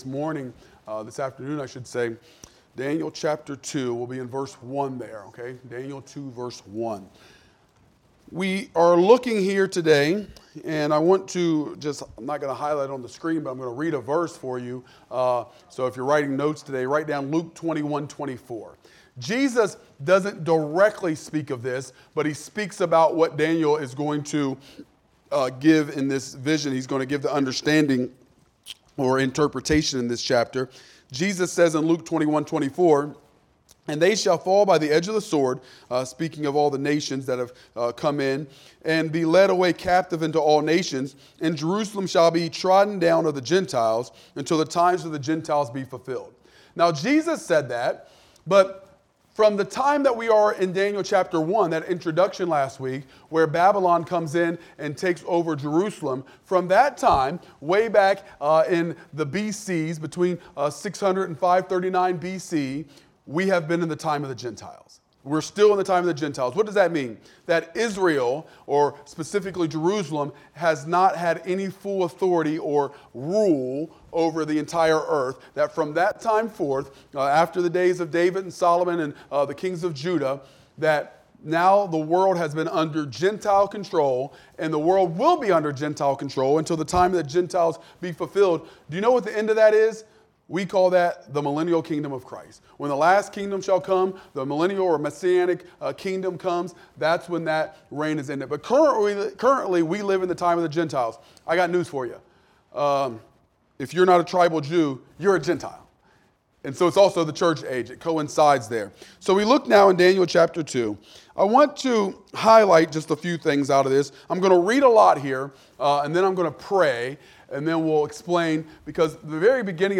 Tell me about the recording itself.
Wednesday Midweek Service